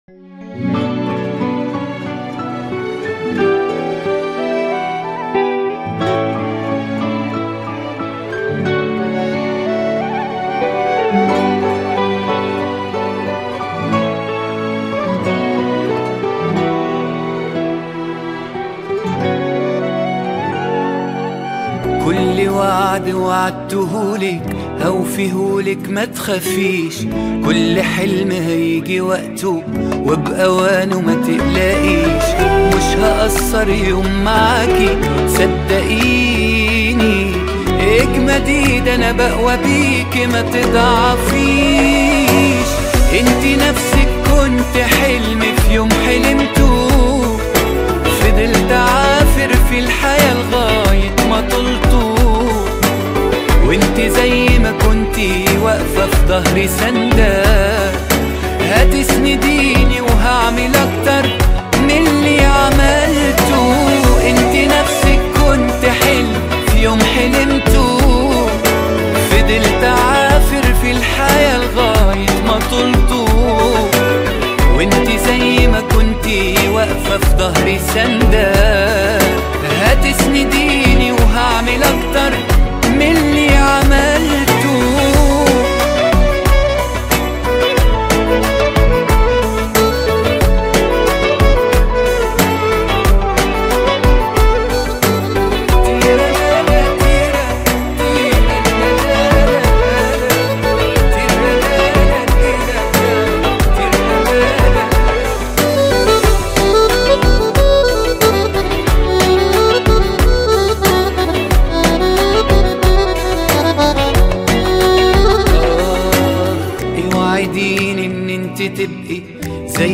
اغاني لبنانيه